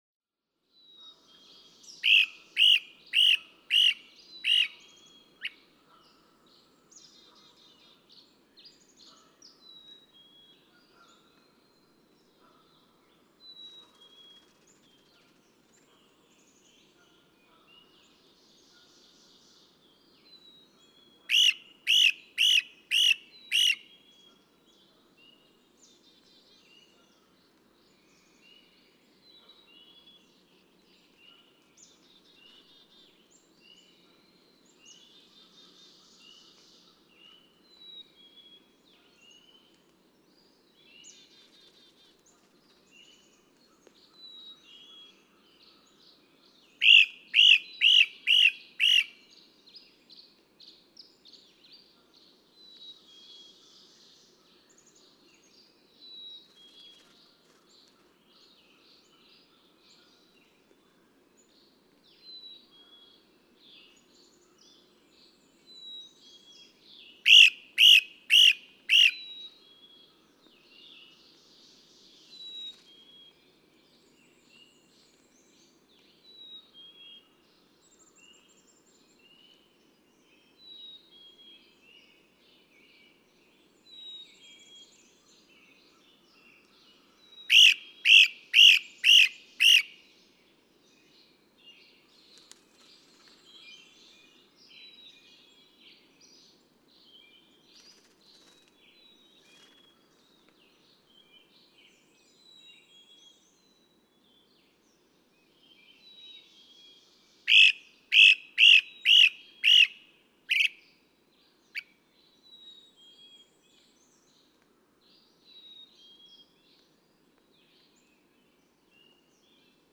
Great crested flycatcher
The occasional daytime raucous outburst, as expected from this flycatcher.
Satan's Kingdom Wildlife Management Area, Northfield, Massachusetts.
477_Great_Crested_Flycatcher.mp3